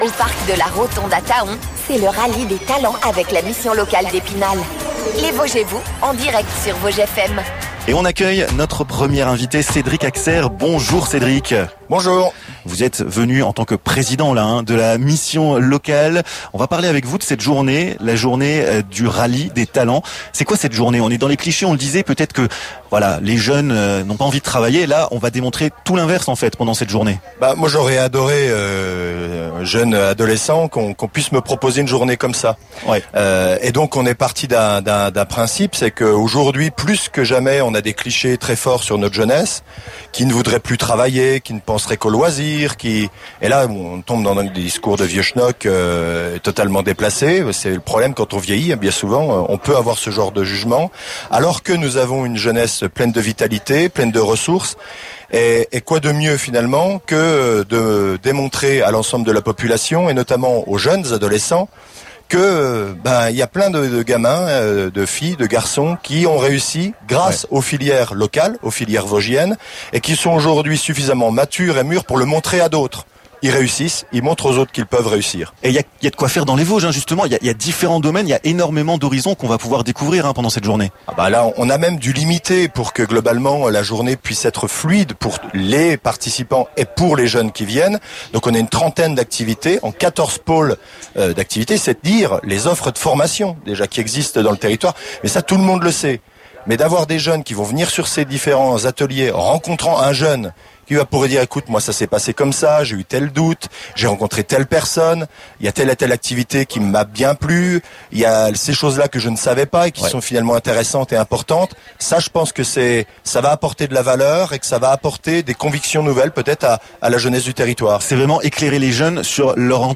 Le 9 juin dernier, Vosges FM était en direct du parc de la Rotonde pour une émission spéciale sur le rallye des talents! Une journée qui a permis la promotion des métiers en tension par des jeunes vers des jeunes, la valorisation de jeunes ayant réussi ainsi qu’une aide à l’orientation par des démonstrations par les jeunes de leur métier ou de leur passion. Retrouvez les interviews de tous nos invités dans ce podcast!